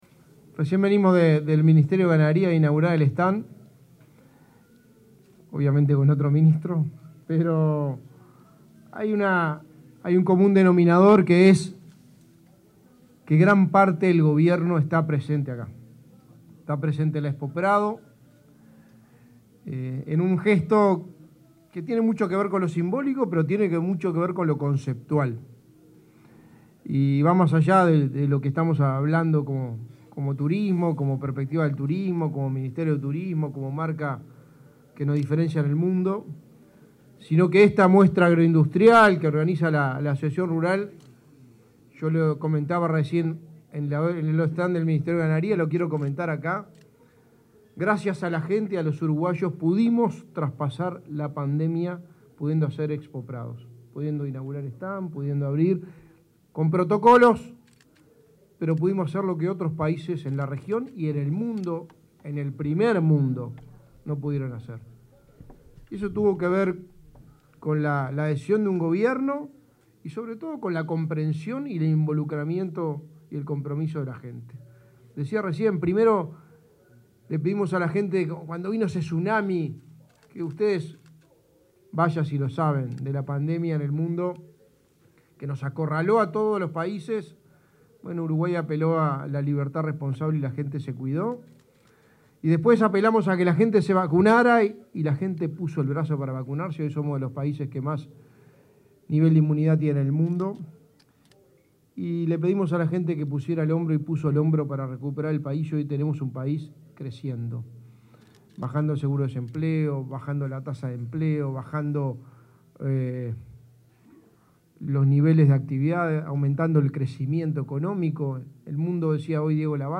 Palabras del secretario de la Presidencia, Álvaro Delgado, en stand del Ministerio de Turismo en la Expo Prado